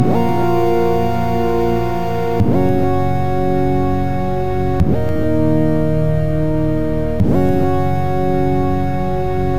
F# (F Sharp Major - 2B) Free sound effects and audio clips
• Synth Texture Glide.wav
Synth_Texture_Glide__K1p.wav